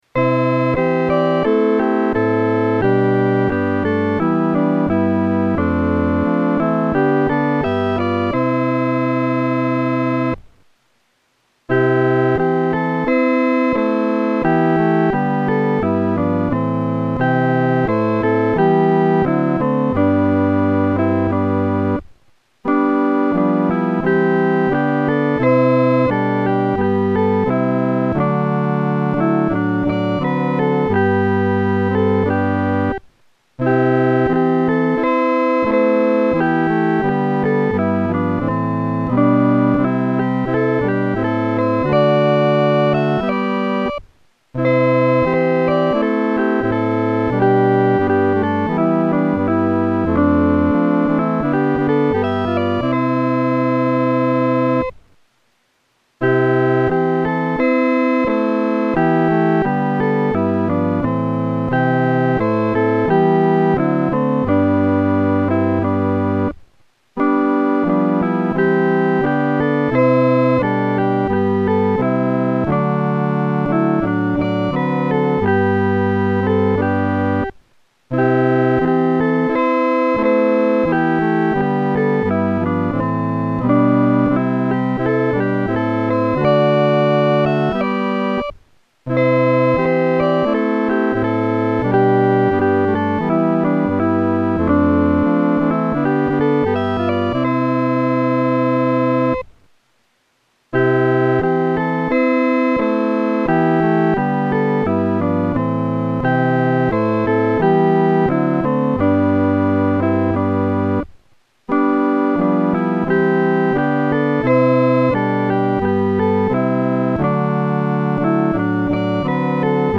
伴奏
四声